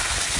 ski-67717.wav